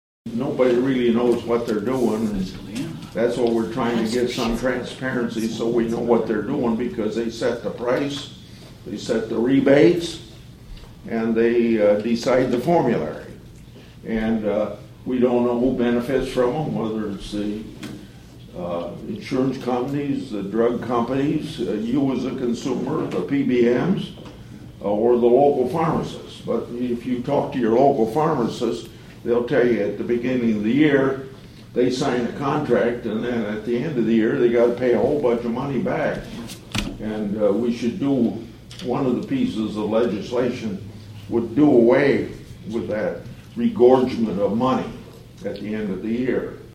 (Atlantic) Senator Chuck Grassley held a town hall meeting at the Iowa Western Community College Cass County campus in Atlantic this (Tuesday) morning.